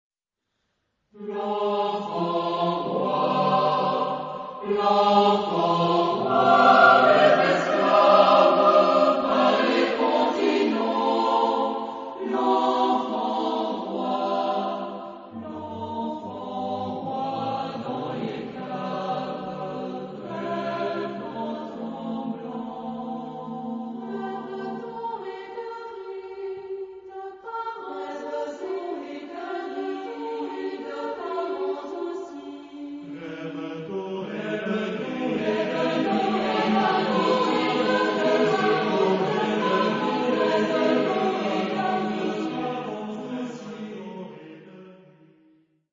Genre-Style-Forme : Profane ; Contrapuntique
Caractère de la pièce : réfléchi
Type de choeur : SATB  (4 voix mixtes )
Tonalité : fa mineur